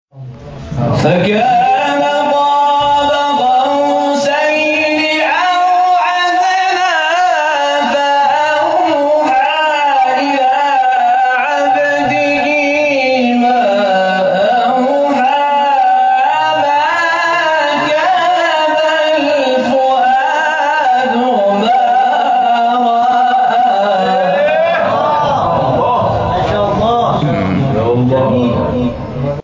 به گزارش خبرگزاری بین‌المللی قرآن(ایکنا) مقاطع صوتی از تلاوت قاریان بین‌المللی و ممتاز کشور که به تازگی در شبکه‌های اجتماعی منتشر شده است، ارائه می‌شود.